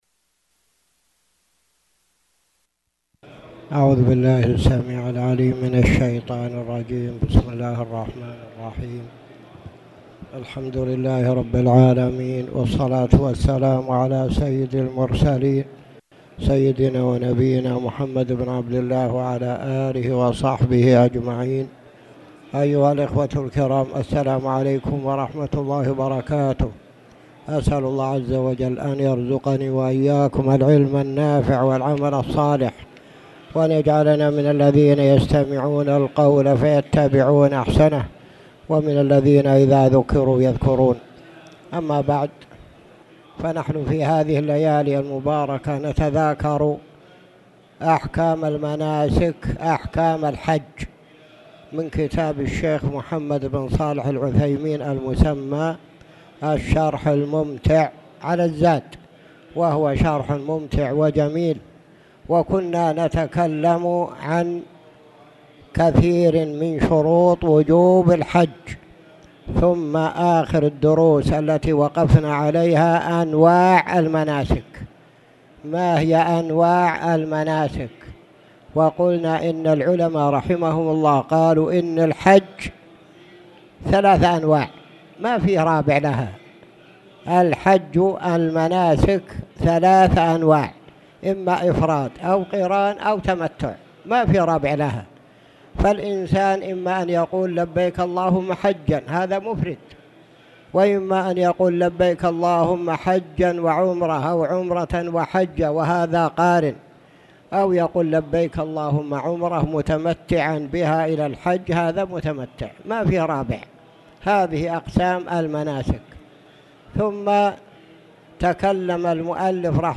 تاريخ النشر ١٤ ذو القعدة ١٤٣٨ هـ المكان: المسجد الحرام الشيخ